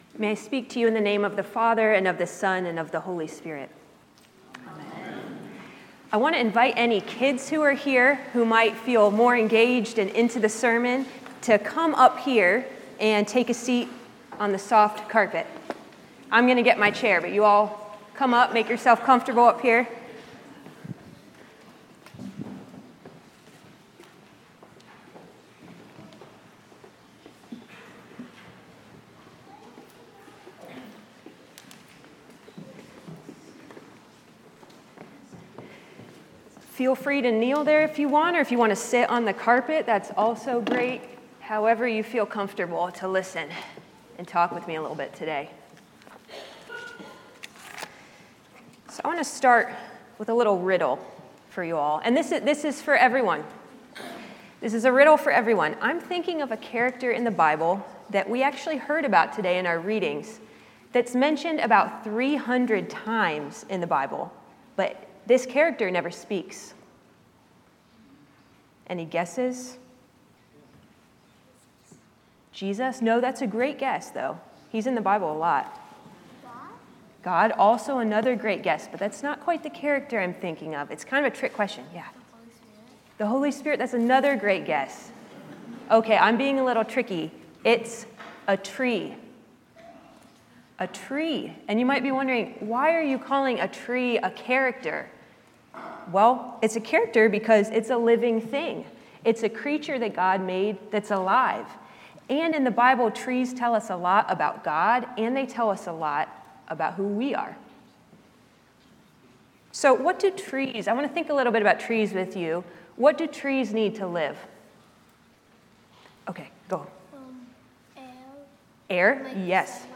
St-Pauls-HEII-9a-Homily-07SEP25.mp3